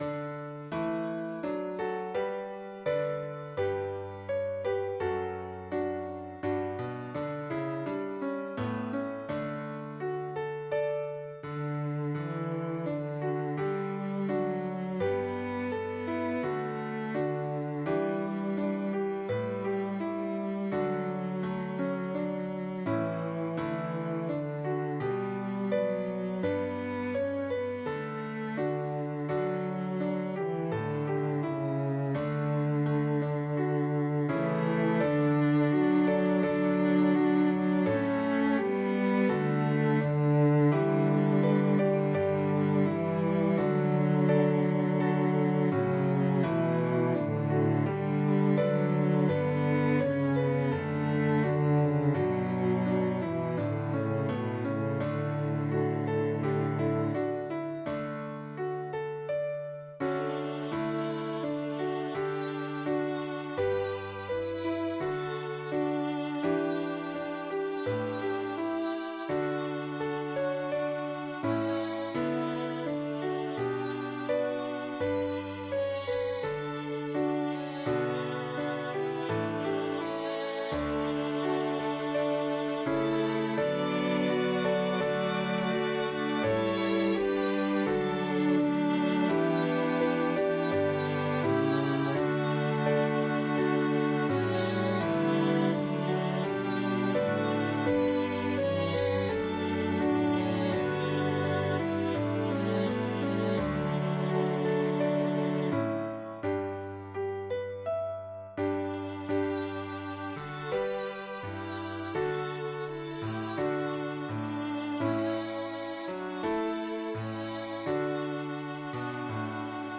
I love the message, but felt this hymn could be more dynamic. I turned the fourth verse into a bridge and stuck it between the second and third verses to add a fresh perspective.
Voicing/Instrumentation: SATB We also have other 9 arrangements of " Behold the Wounds in Jesus' Hands ".